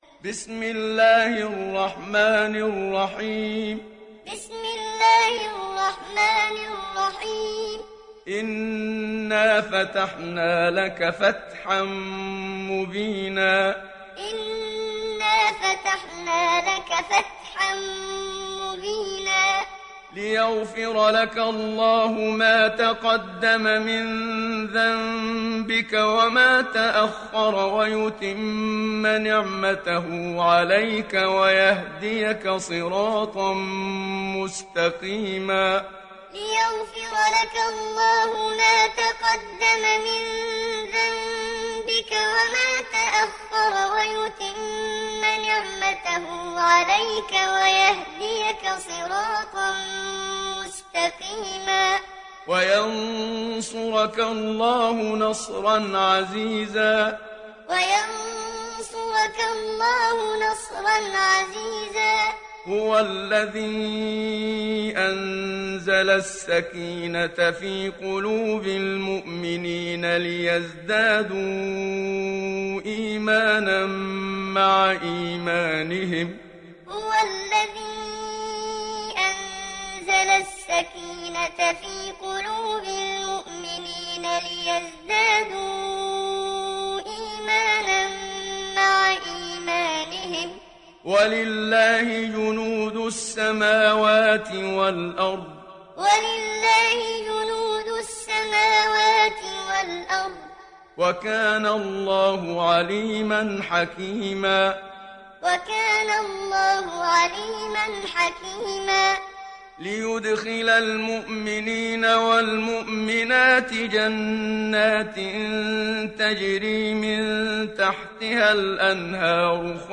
دانلود سوره الفتح محمد صديق المنشاوي معلم